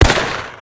fire_stereo.wav